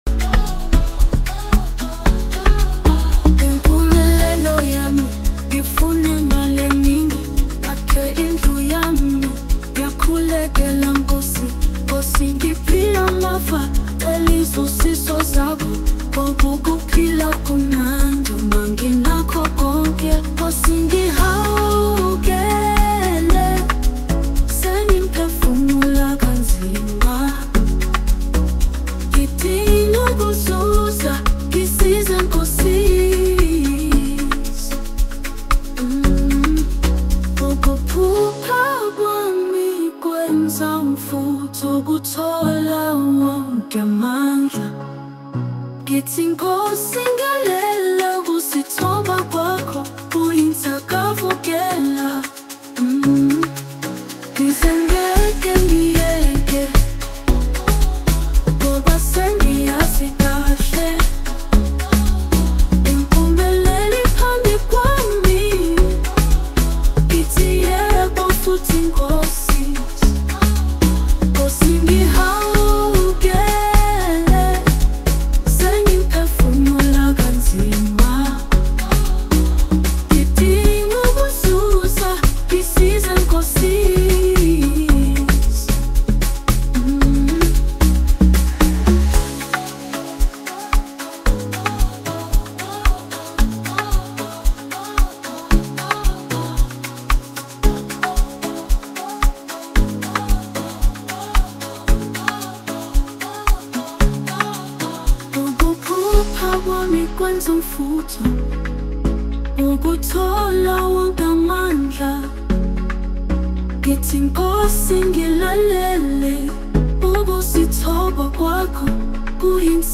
South African Dj crew
the beat is a filly amapiano infused track“”..